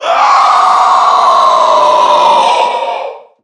NPC_Creatures_Vocalisations_Puppet#4 (hunt_04).wav